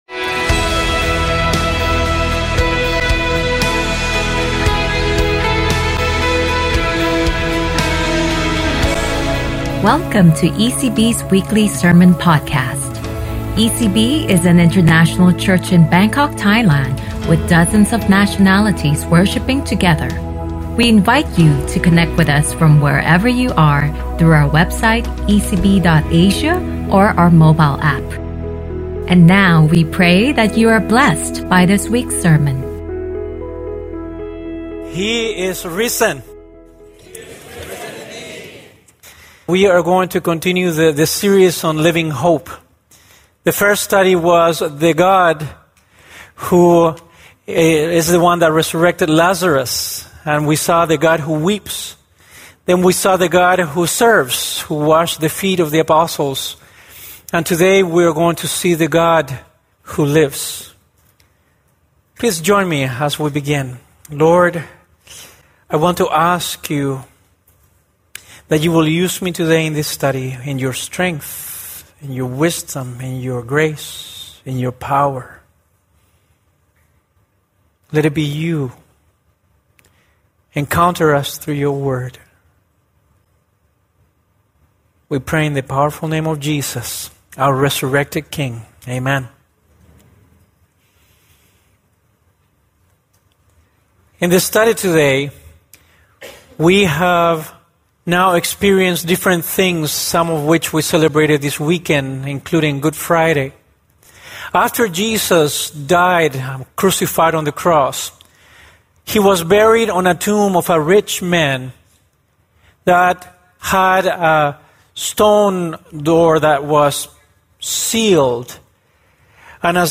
ECB Sermon Podcast